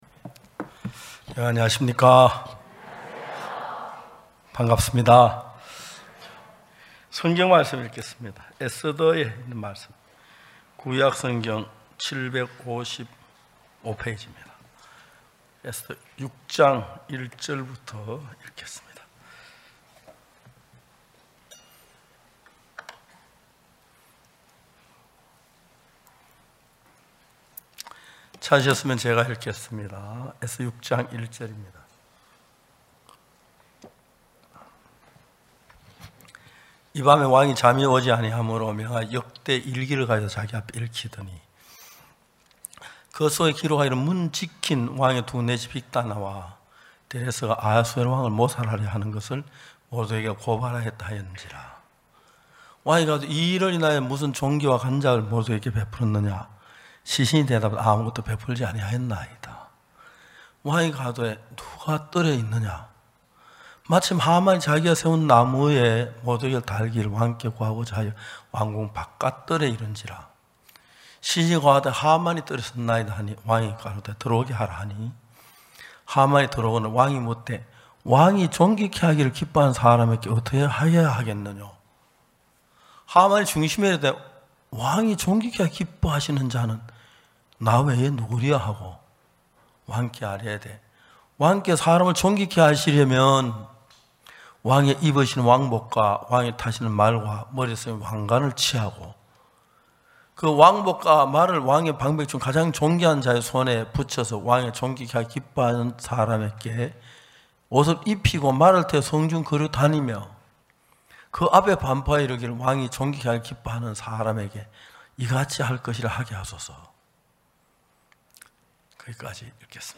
우리를 지키시는 하나님 / 서울지역 연합예배
전국 각 지역의 성도들이 모여 함께 말씀을 듣고 교제를 나누는 연합예배.